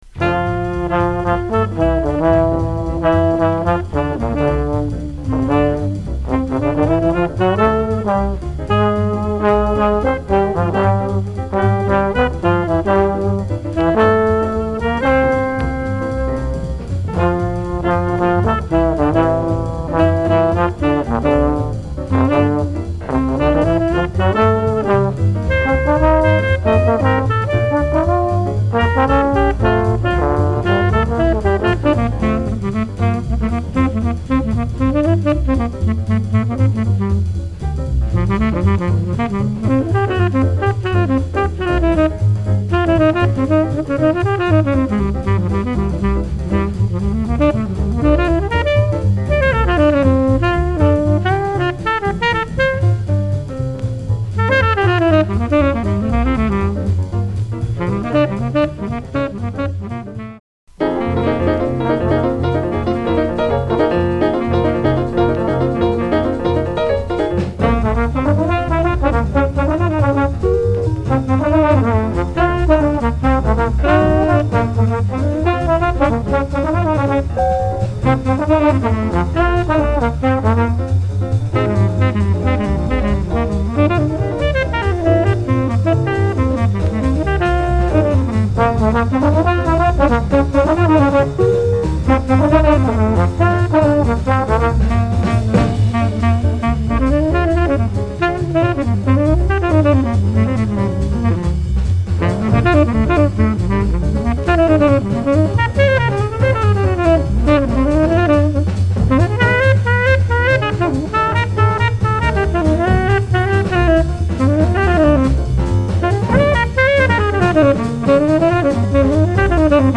mono盤はモノラルMCカートリッジ、stereo盤はステレオMCカートリッジでの評価となります。
Press：US
Format：LP